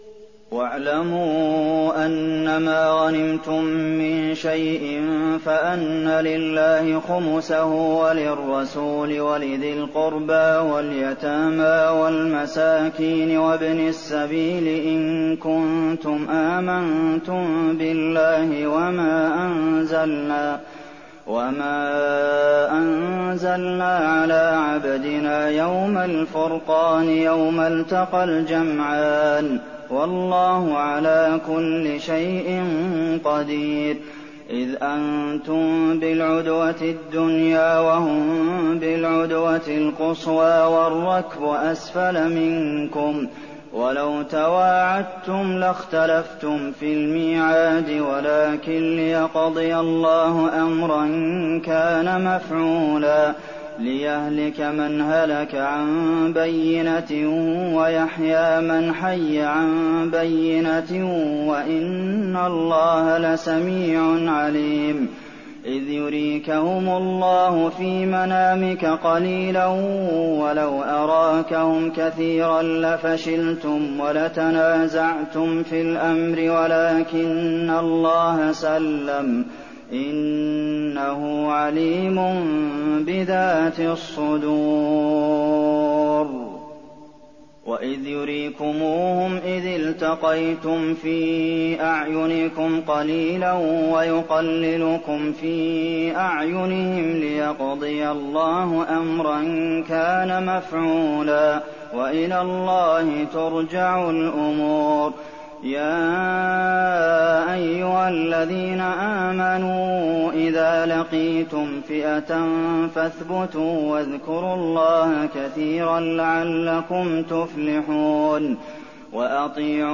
تراويح الليلة العاشرة رمضان 1419هـ من سورتي الأنفال (41-75) و التوبة (1-33) Taraweeh 10th night Ramadan 1419H from Surah Al-Anfal and At-Tawba > تراويح الحرم النبوي عام 1419 🕌 > التراويح - تلاوات الحرمين